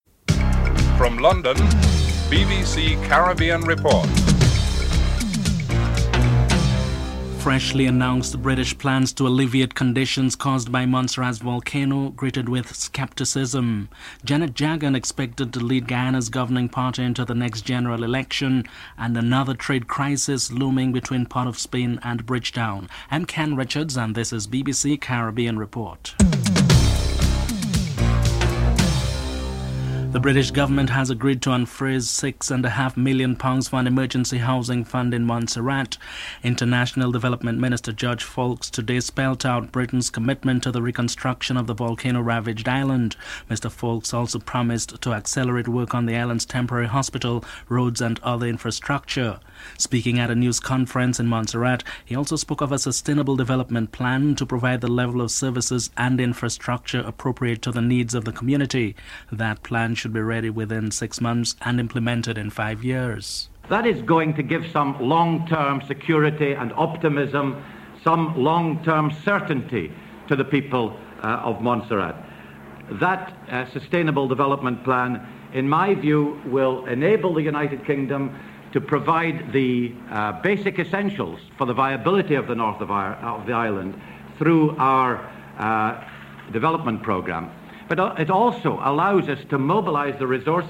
1. Headlines (00:00-00:29)
Member of Parliament, Bernie Grant is interviewed (06:17-09:07)